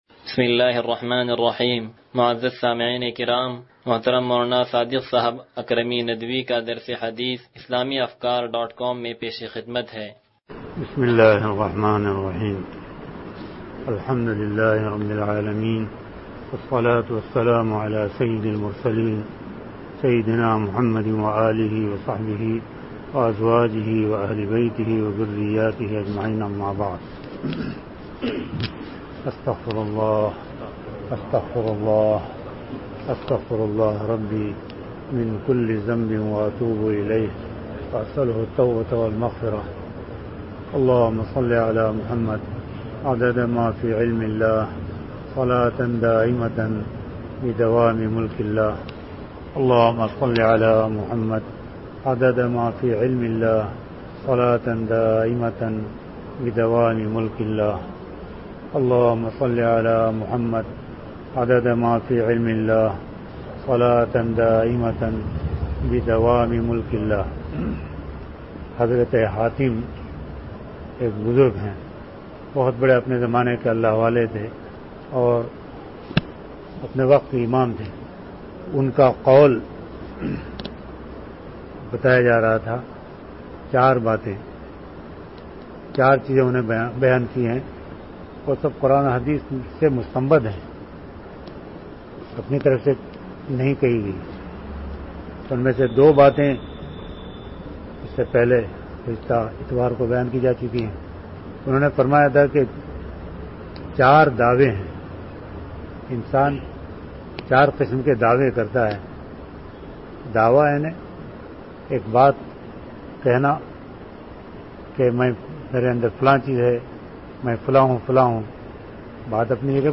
درس حدیث نمبر 0066